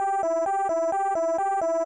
Alarm
alarm_2.ogg